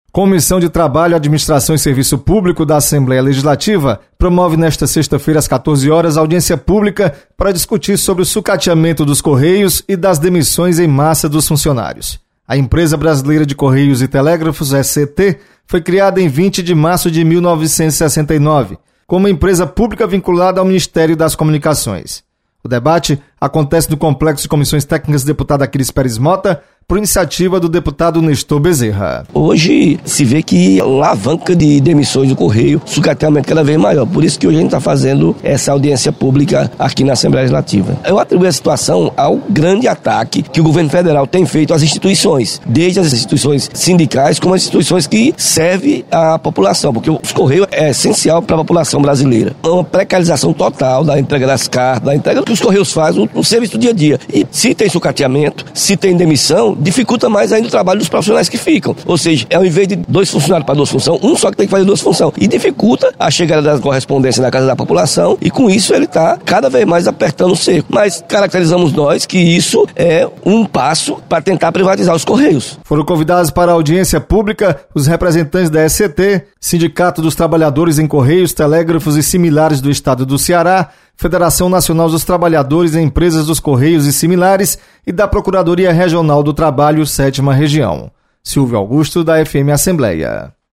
Comissão debate situação da Empresa Brasileira de Correios e Telégrafos (ECT). Repórter